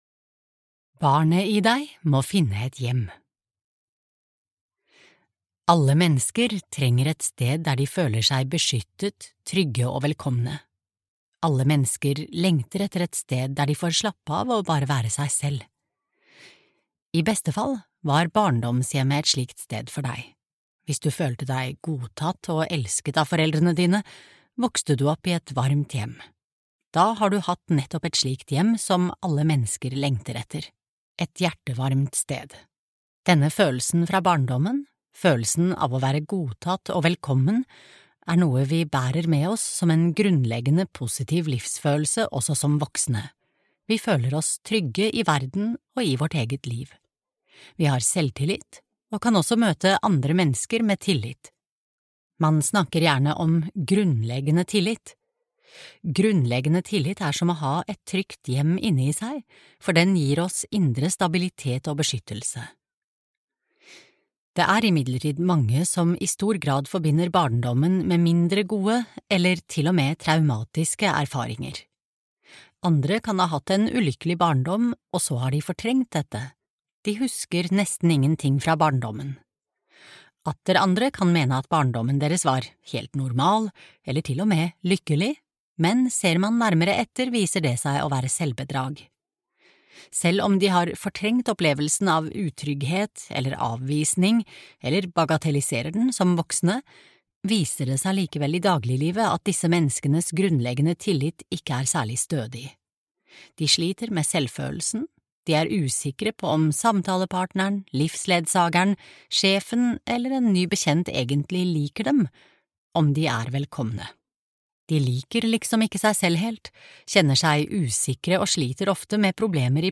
Barnet i deg må finne et hjem - nøkkelen til å løse (nesten) alle problemer (lydbok) av Stefanie Stahl